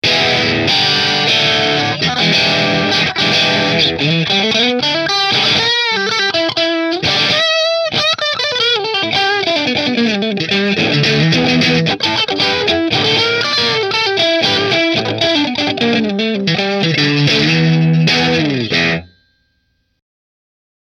• HSS DiMarzio Pickup Configuration
B3 Metal XS Kerry Green Position 4 Through Marshall